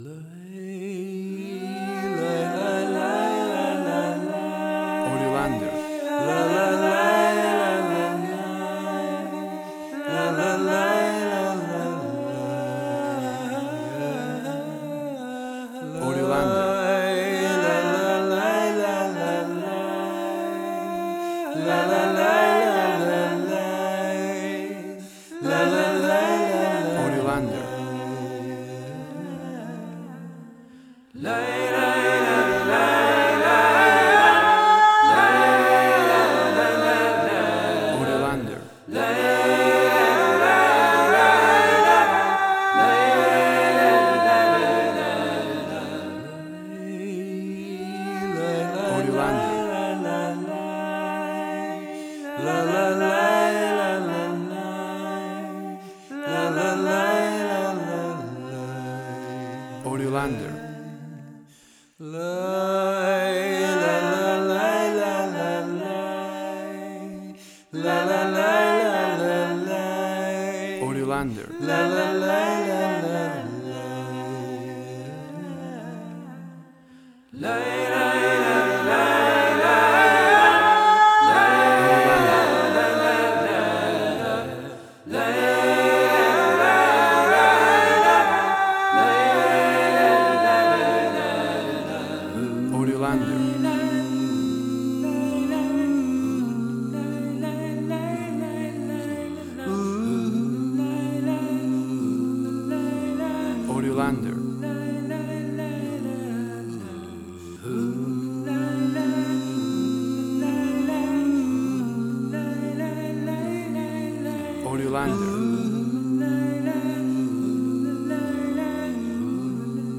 emotional music